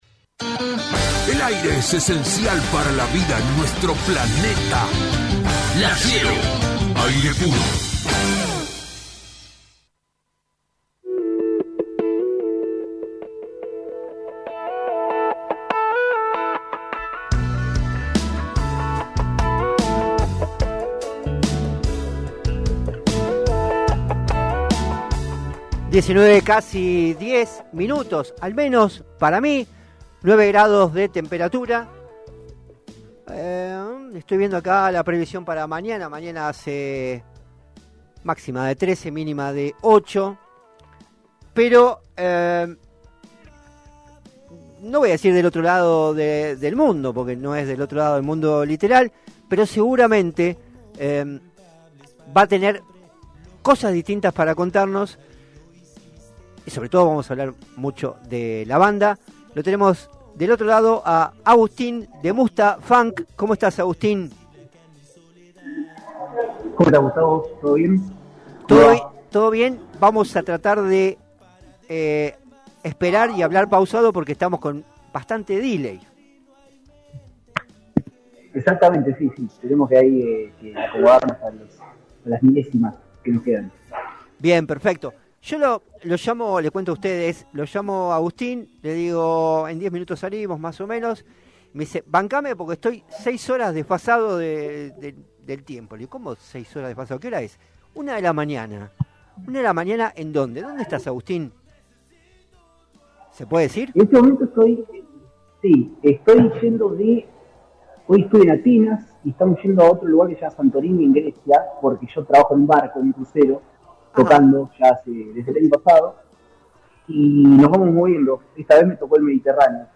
Entrevista-a-Mustafunk.mp3